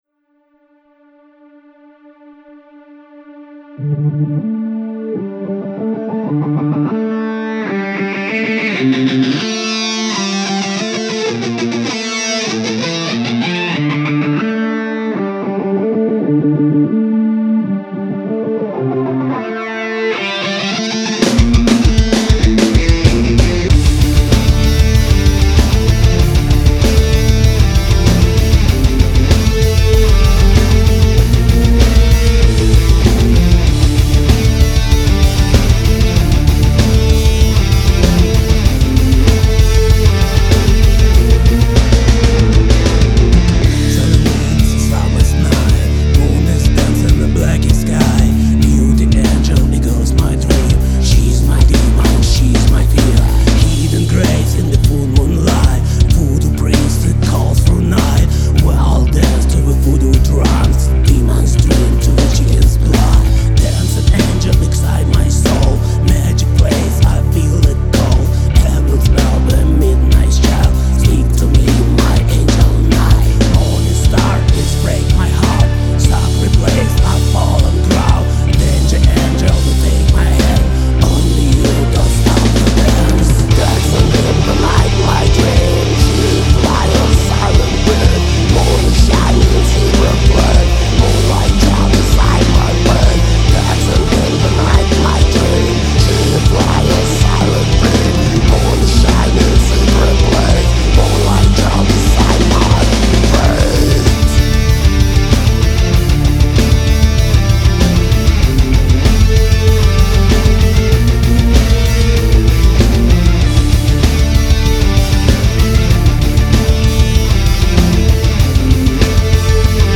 Зацените кусочек трека плиз (metal industrial)